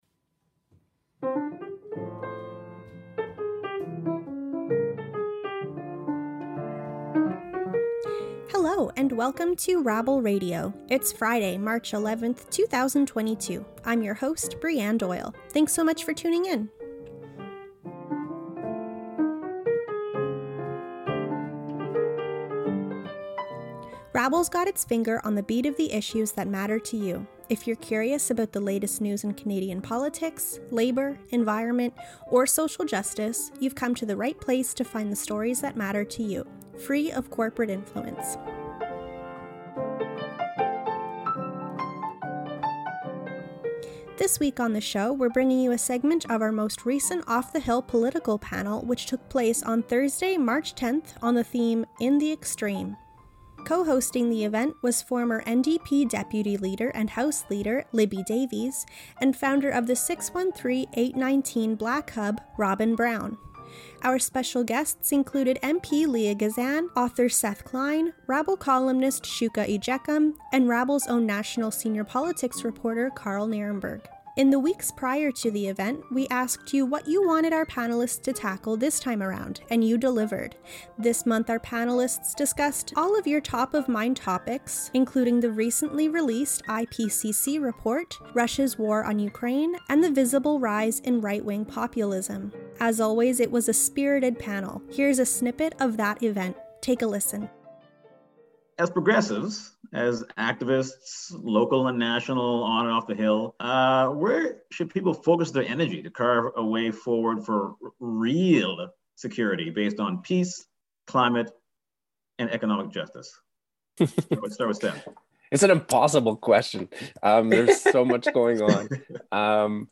Panelists discuss the IPCC report, Russia's war on Ukraine and the rise of the alt-right in Canada